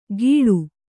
♪ gīḷu